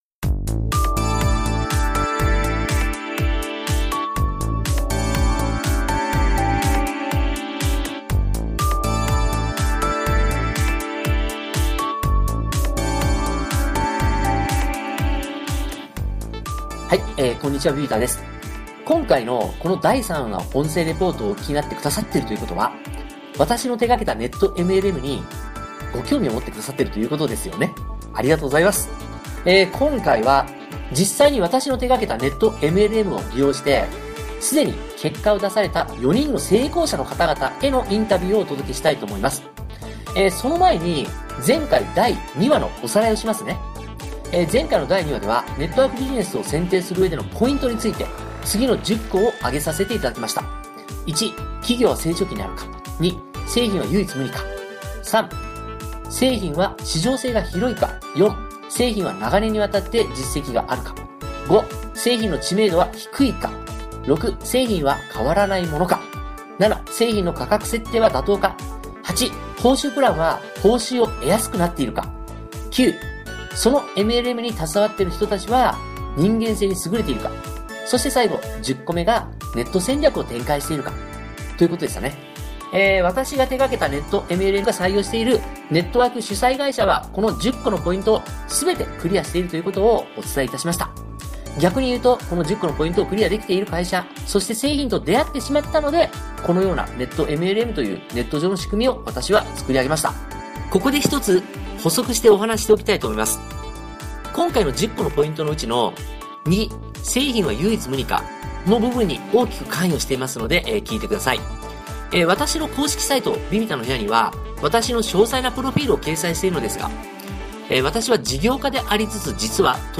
成功者の独占インタビューになります。
は、 少し難しかったかもしれませんね…。 4人の構成は、男性2人、女性2人の計4名です。